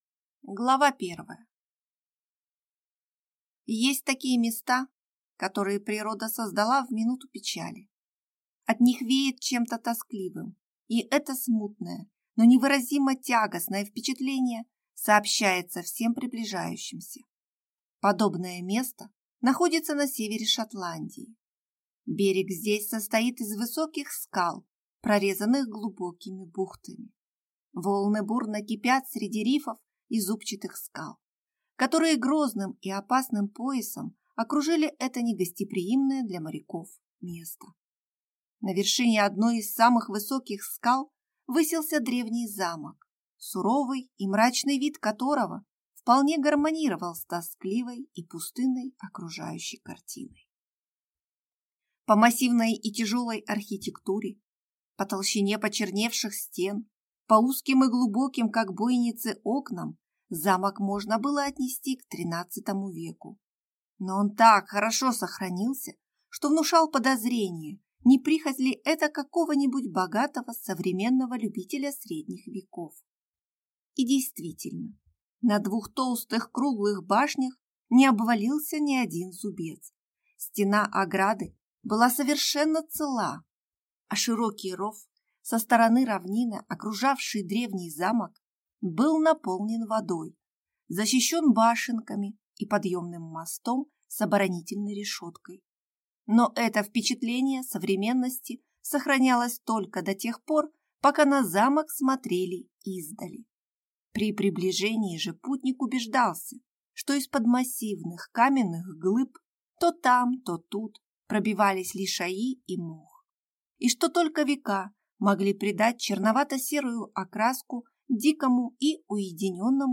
Аудиокнига Маги | Библиотека аудиокниг